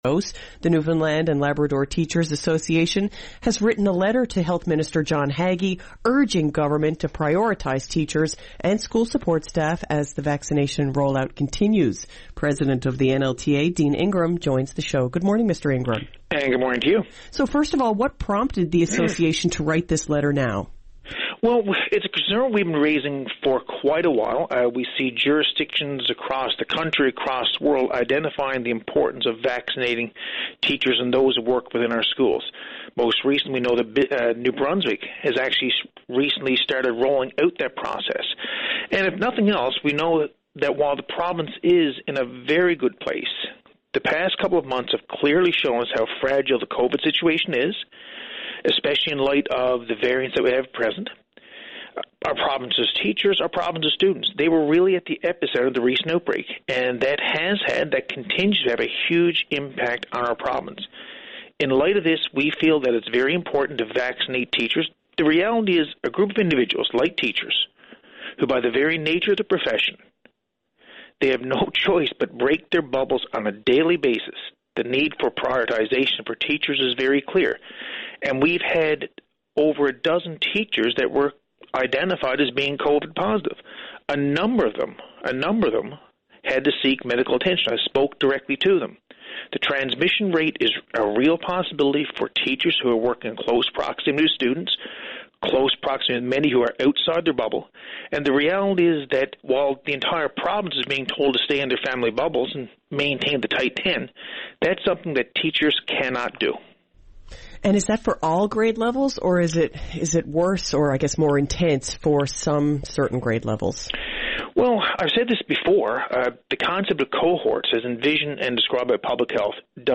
Media Interview - VOCM Morning Show Mar 24, 2021